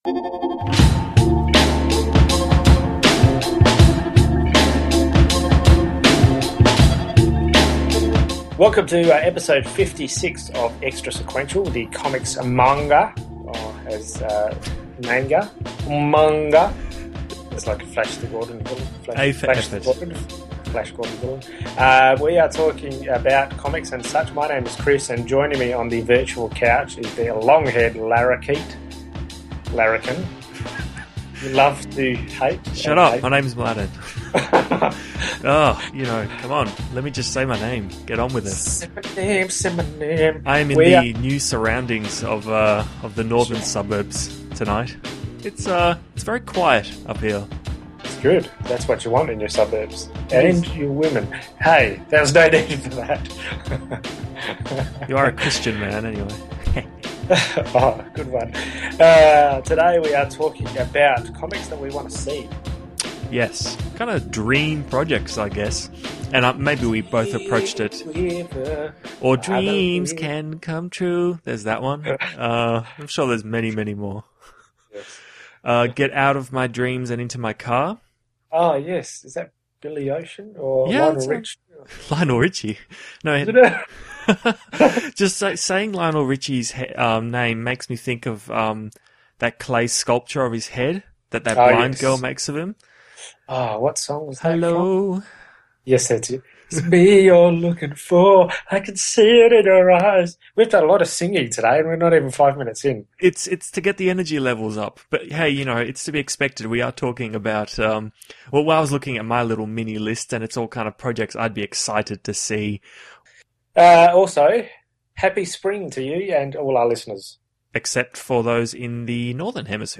After a few brief news items we launch into comics we’d like to see some day. This involves dream projects, concept variations and fond memories of 80s cartoons and forgotten 90s TV shows. We also sing.